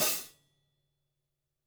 013_mdk_hatclosed22.wav